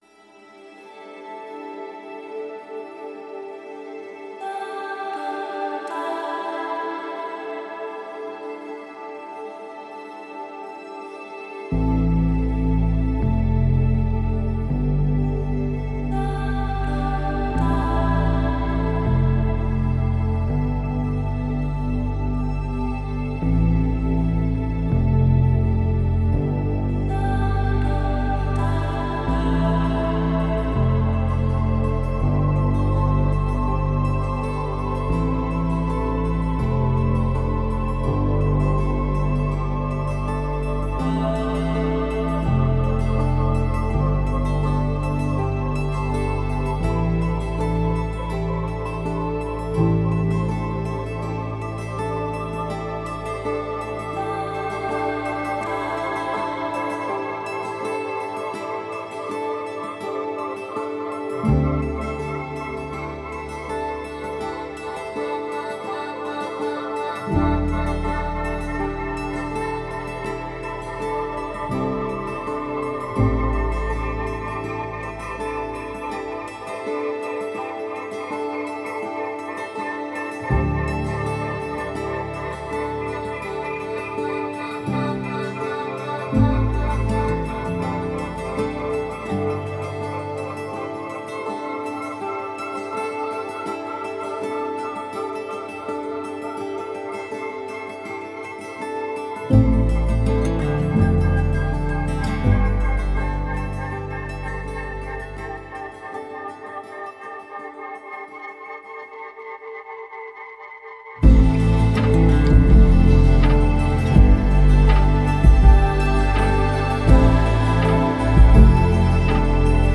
Surrounded by sounds of an electronic natural wonder.